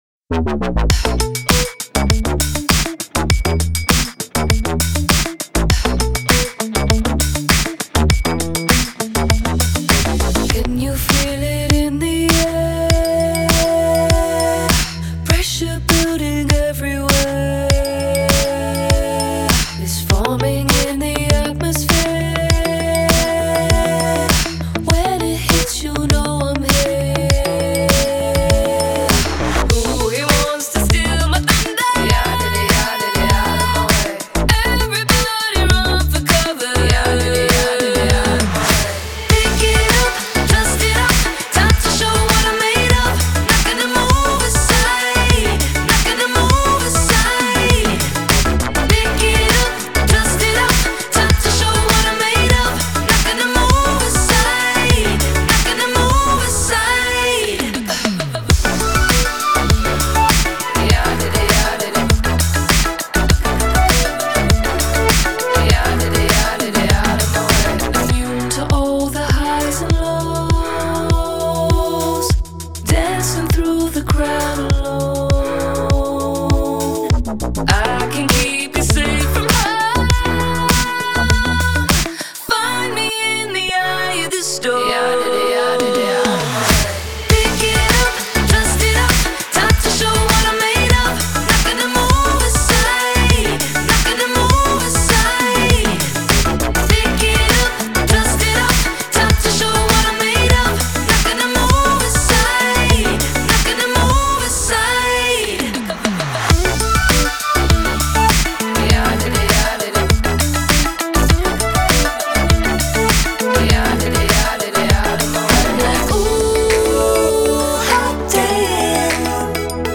это яркая композиция в жанре поп-диско